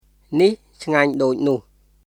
[ニヒ・チガニュ・ドーイ・ヌフ　nih cʰŋaɲ doːc nuh]